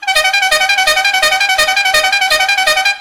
coche_choque.wav